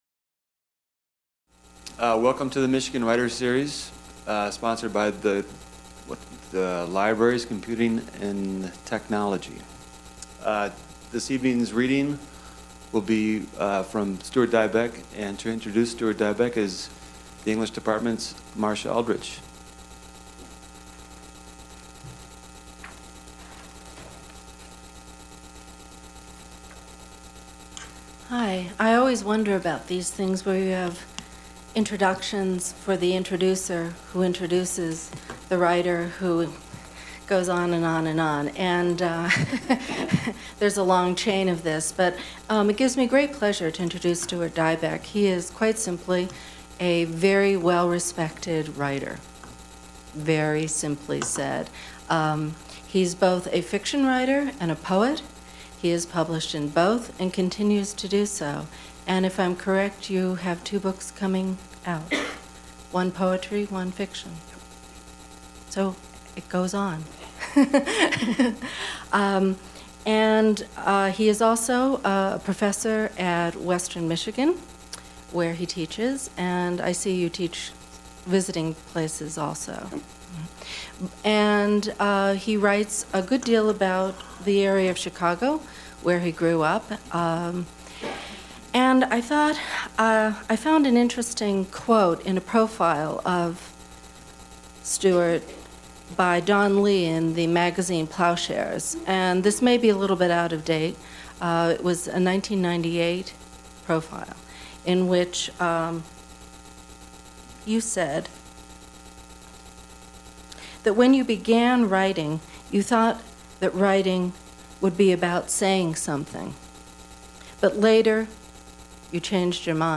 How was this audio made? Held in the Main Library. Recordist Vincent Voice Library Material Type Sound recordings Series Michigan writers series Language English Extent 00:49:32 Venue Note Recorded at the Michigan State University Libraries by the Vincent Voice Library on Apr. 18, 2003.